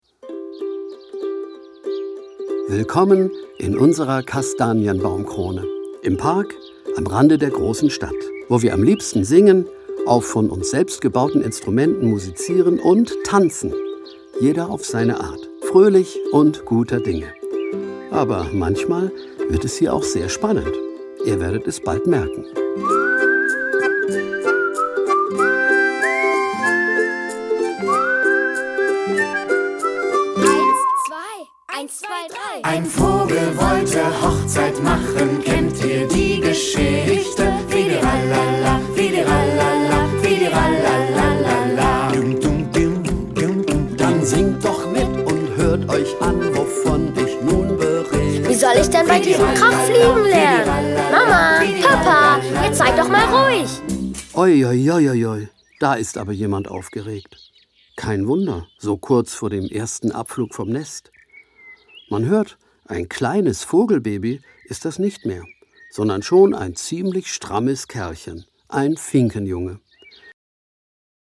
Entstanden ist ein lustiges Musikhörspiel
Musik